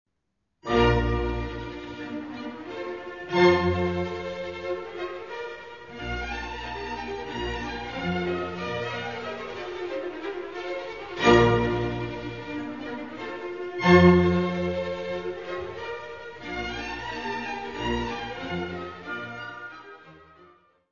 Herbert Von Karajan; Berliner Philharmoniker
Music Category/Genre:  Classical Music